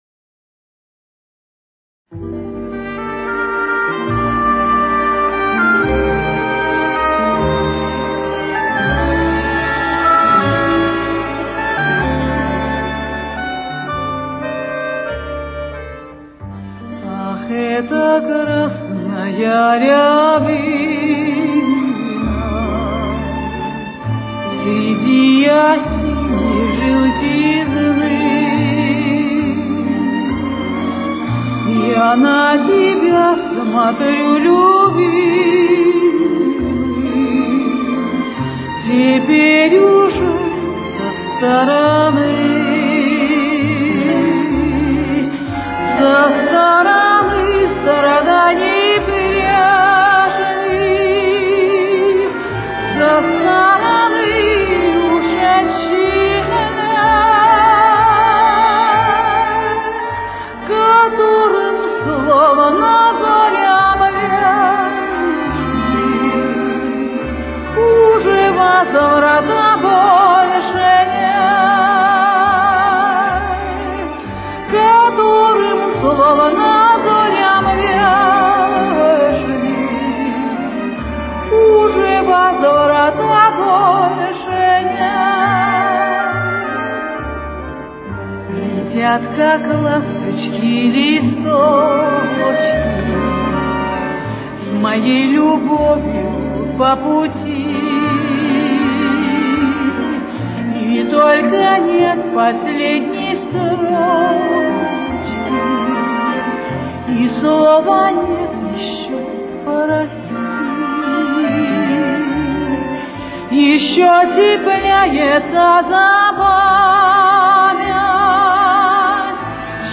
Соль минор. Темп: 69.